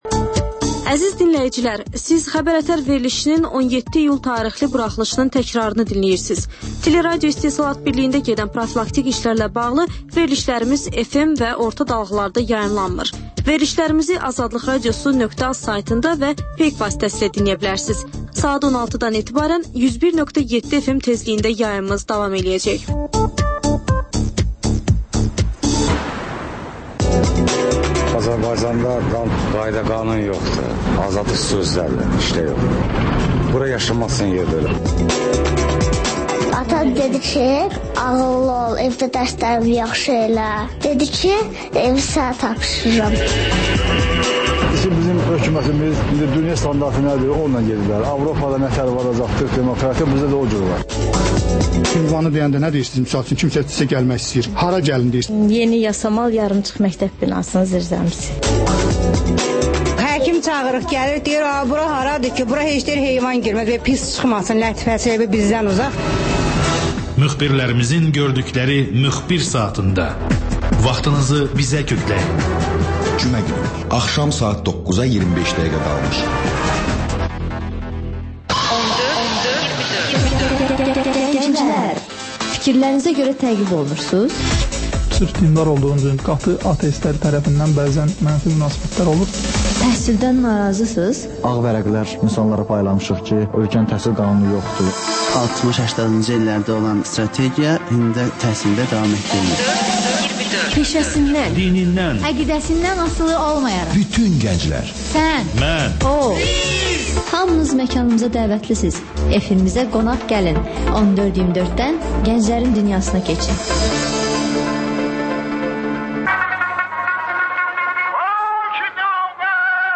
Xəbər-ətər: xəbərlər, müsahibələr, sonra TANINMIŞLAR rubrikası: Ölkənin tanınmış simaları ilə söhbət